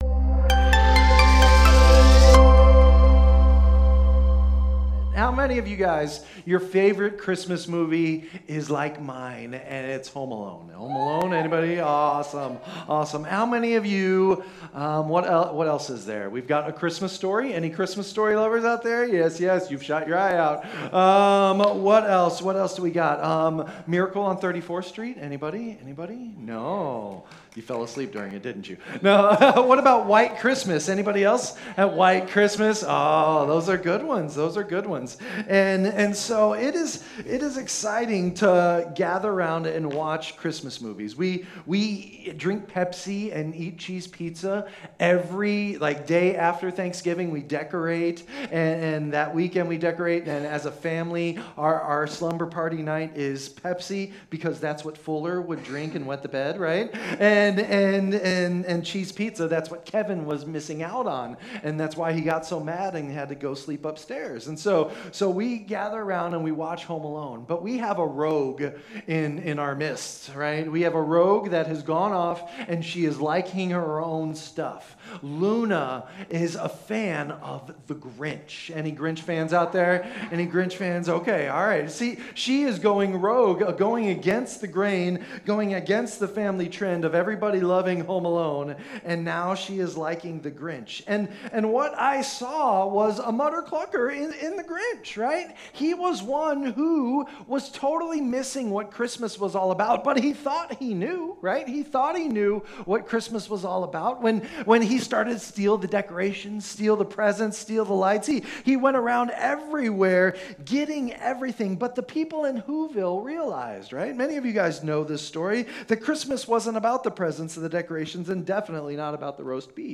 Sermons | Innovation Church